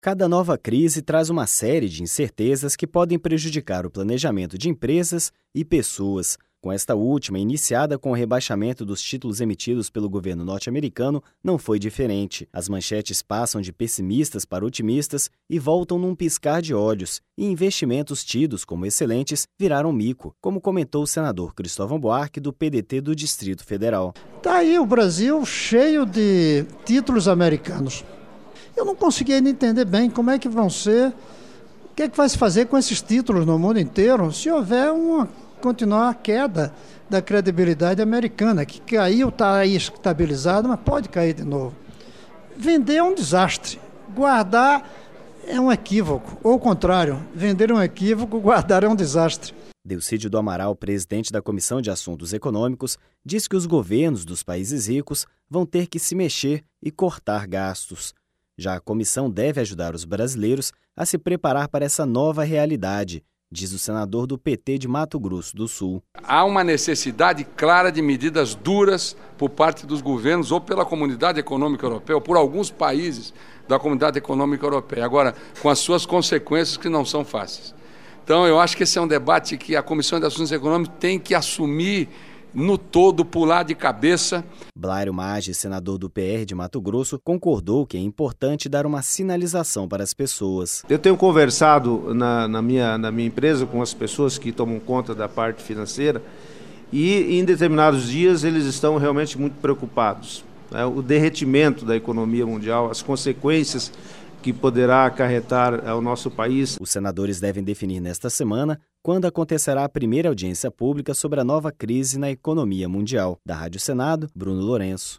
(REPÓRTER) Blairo Maggi, senador do PR de Mato Grosso, concordou que é importante dar uma sinalização para as pessoas.